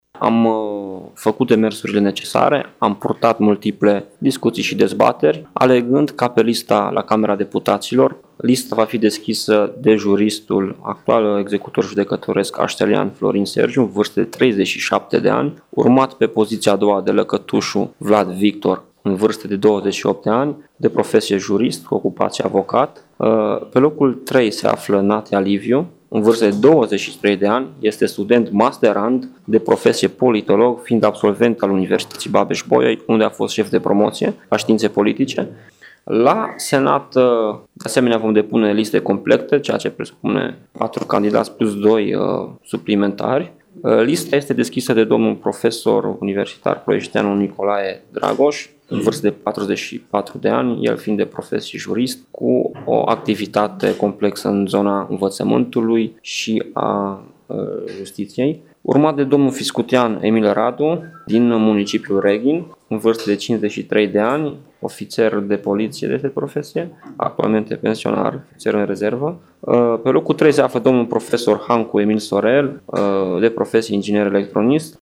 într-o conferință de presă